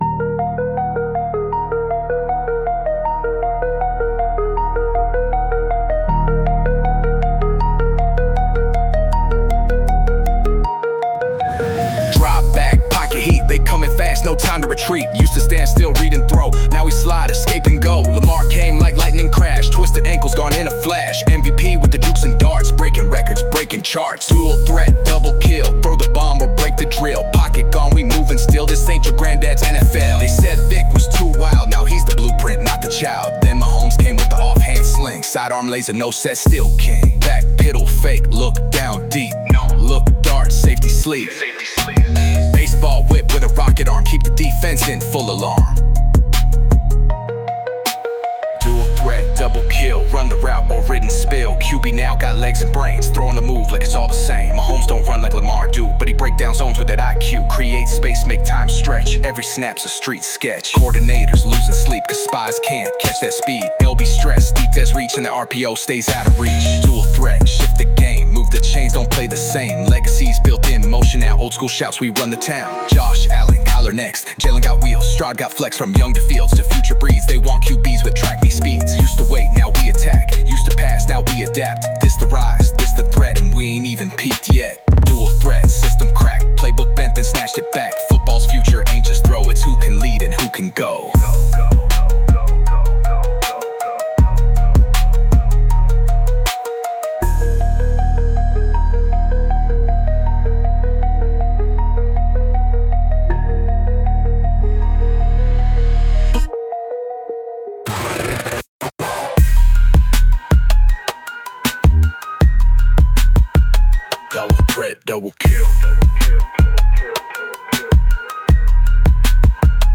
original rap tribute